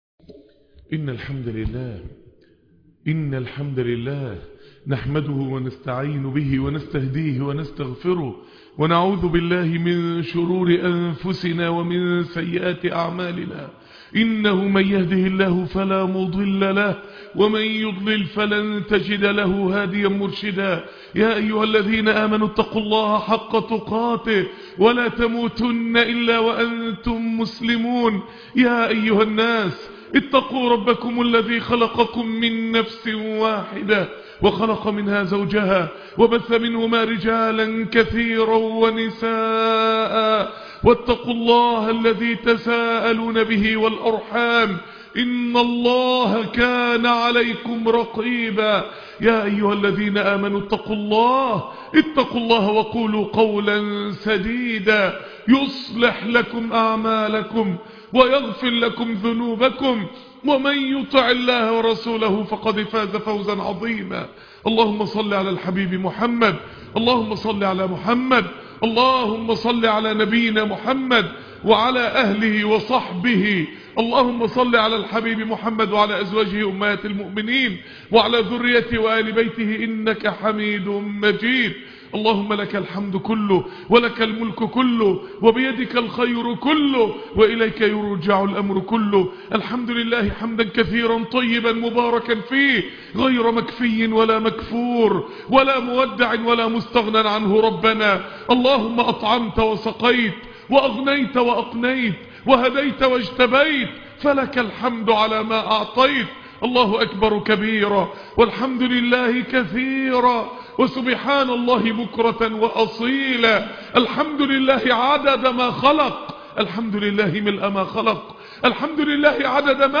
بالضربة القاضية -- .. خطبة -جمعة ..